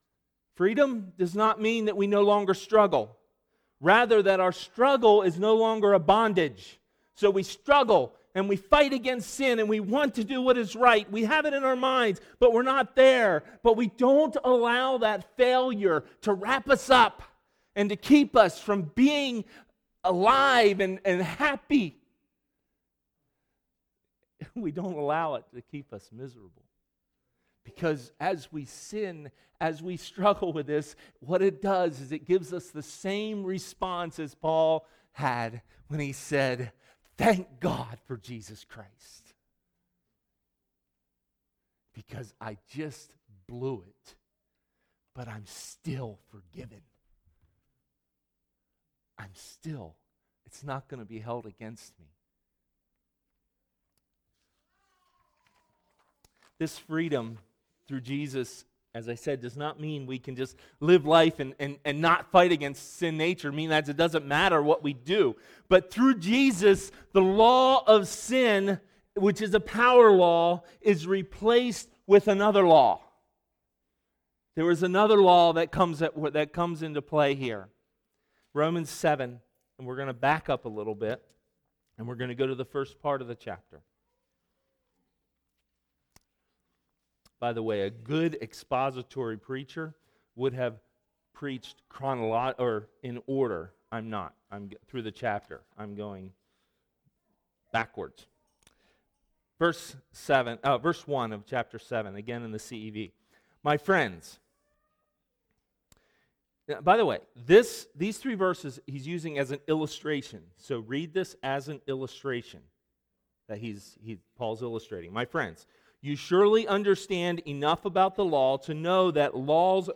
Maranatha Fellowship's Sunday Morning sermon recordings.